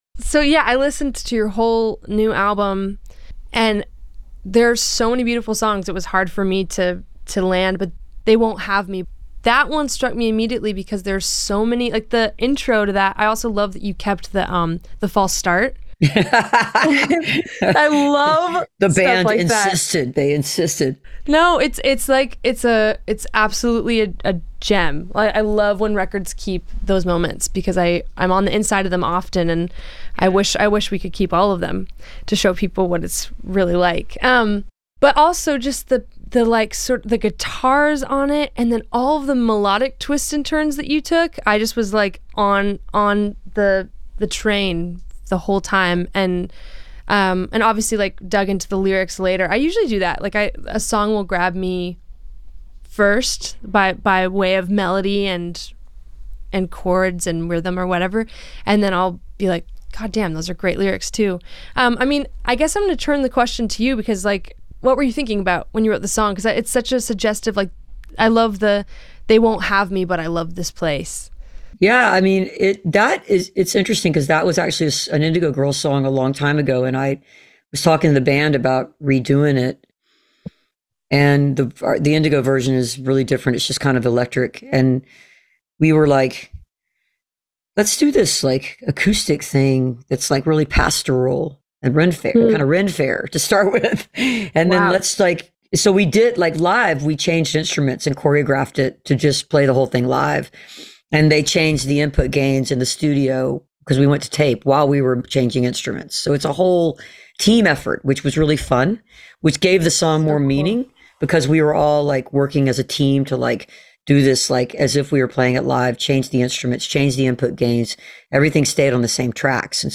(audio capture from web stream)
07. conversation (amy ray and madison cunningham) (5:00)